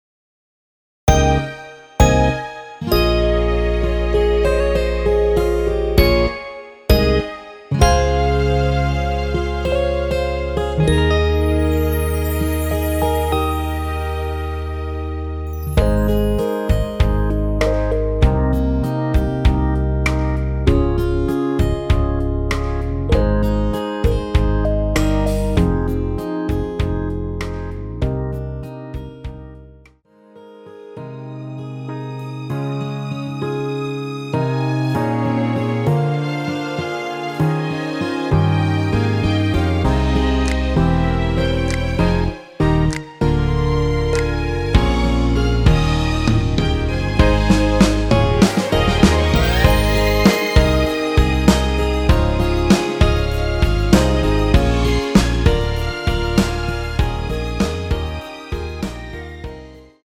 홈페이지에 등록된 여자키에서(+2)더 올린 MR입니다.
원키에서(+6)올린 MR입니다.
Ab
앞부분30초, 뒷부분30초씩 편집해서 올려 드리고 있습니다.